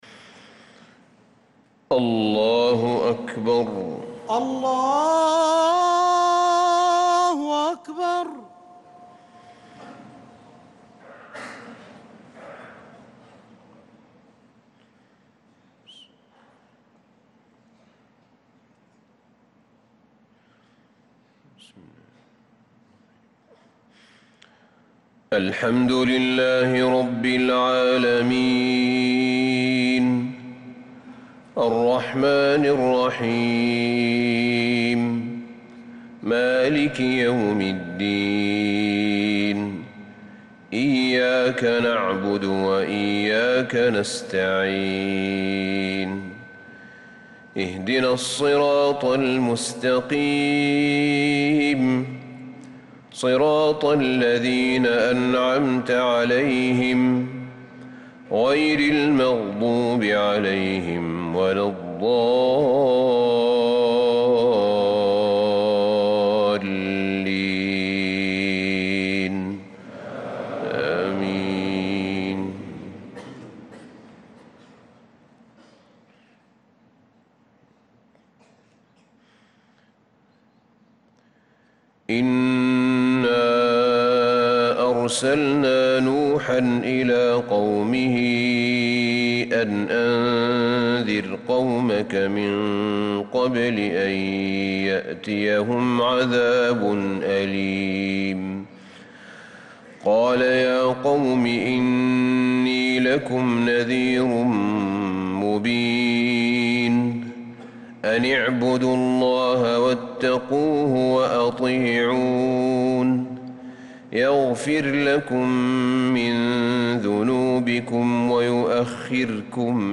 صلاة الفجر للقارئ أحمد بن طالب حميد 16 صفر 1446 هـ